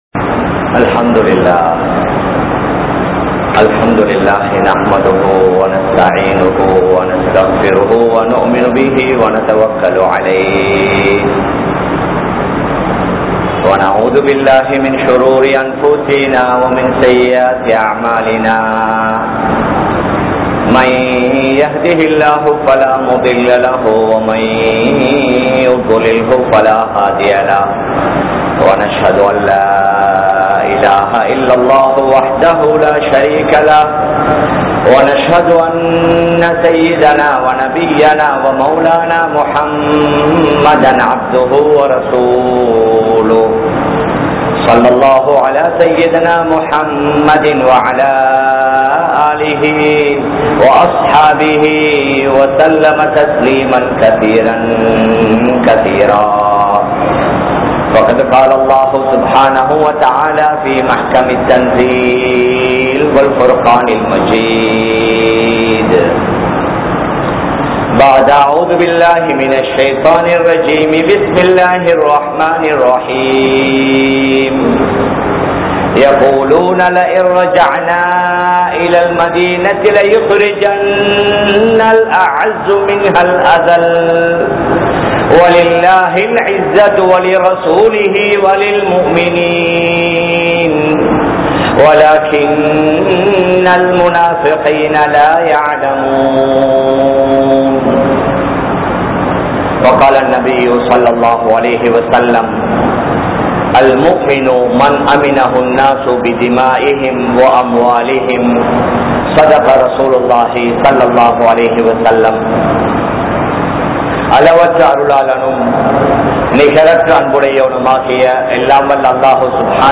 Muslimkale! Ottrumai Padungal(முஸ்லிம்களே! ஒற்றுமைப்படுங்கள்) | Audio Bayans | All Ceylon Muslim Youth Community | Addalaichenai
Kollupitty Jumua Masjith